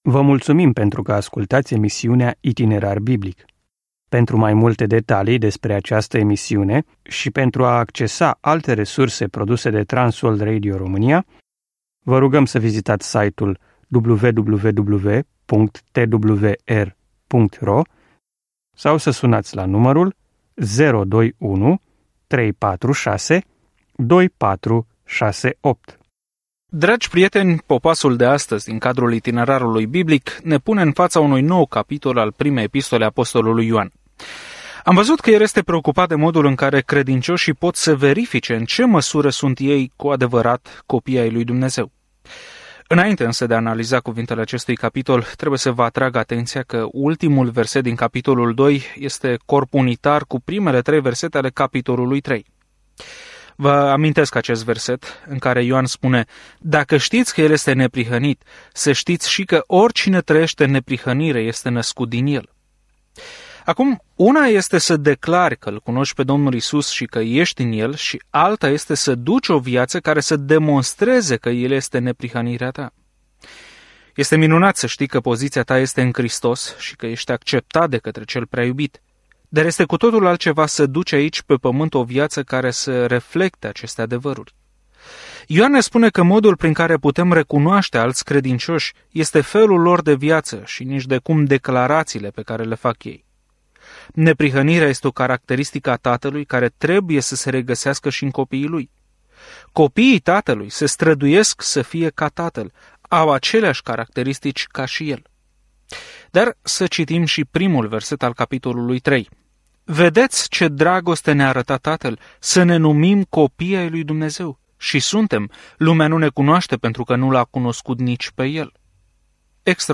Scriptura 1 Ioan 3:1-4 Ziua 9 Începe acest plan Ziua 11 Despre acest plan Nu există cale de mijloc în această primă scrisoare a lui Ioan - fie alegem lumina sau întunericul, adevărul în fața minciunii, iubirea sau ură; Îl îmbrățișăm pe unul sau pe altul, așa cum fie credem, fie nepădăm pe Domnul Isus Hristos. Călătoriți zilnic prin 1 Ioan în timp ce ascultați studiul audio și citiți versete selectate din Cuvântul lui Dumnezeu.